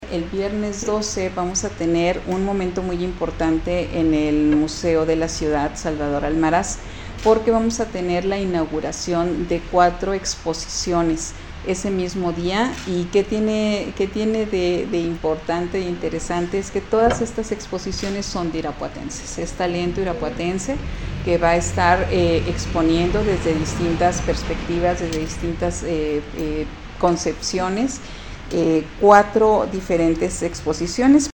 AudioBoletines
Gloria Cano, directora del IMCAR